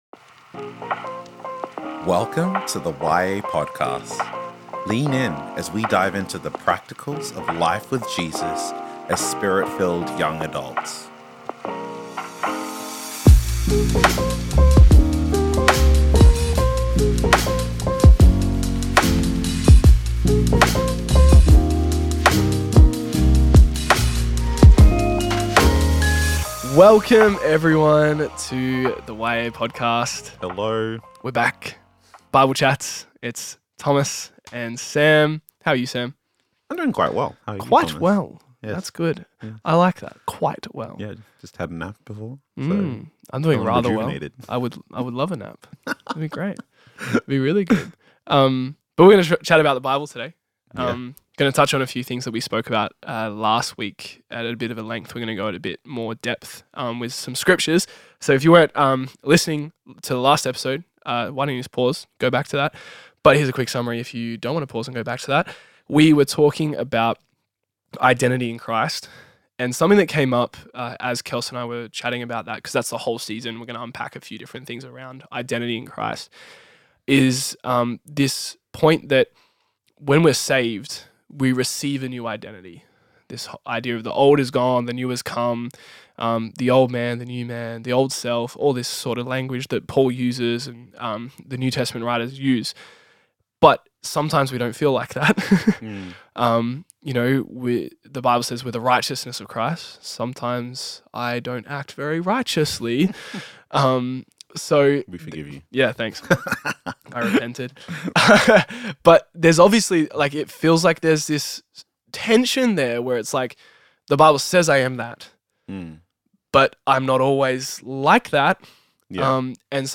Bible Chats: Transformation into Christlikeness